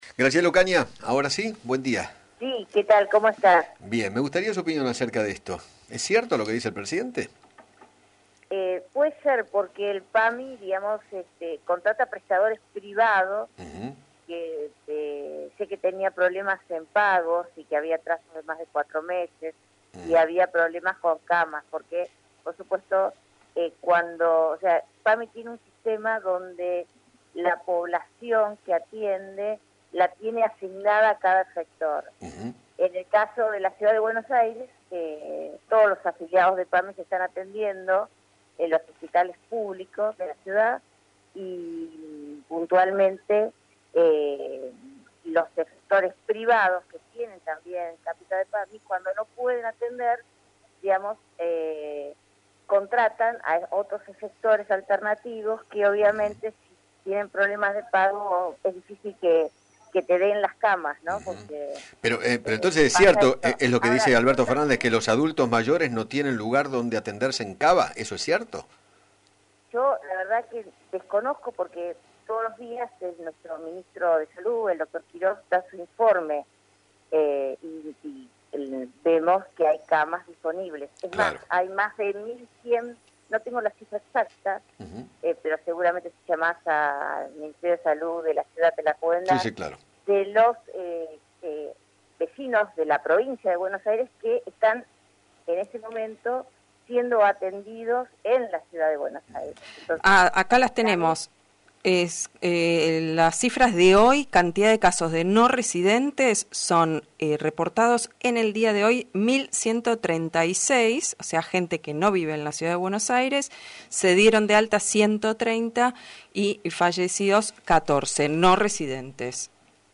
Graciela Ocaña, Diputada Nacional, dialogó con Eduardo Feinmann sobre las declaraciones del presidente, quien aseguró que “los adultos mayores que se enferman de Covid en la Ciudad tienen que atenderse en Provincia porque en Ciudad no hay más lugar”.